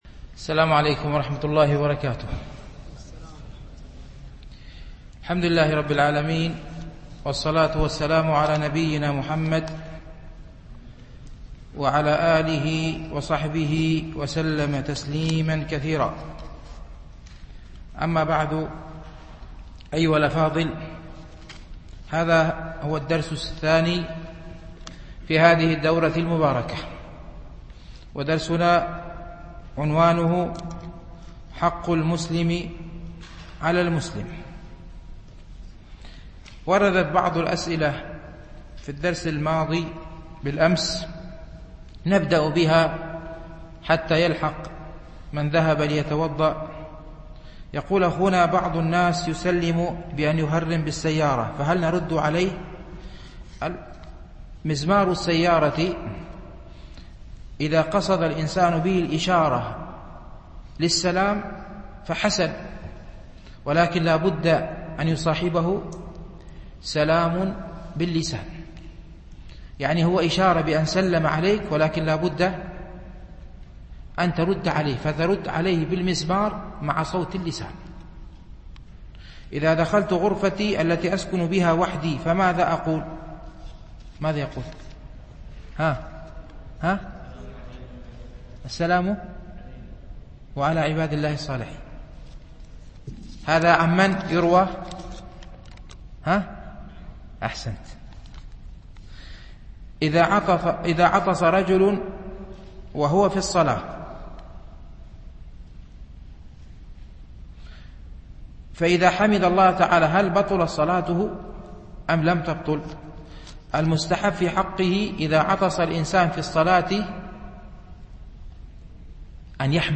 حق المسلم على المسلم ـ الدرس الثاني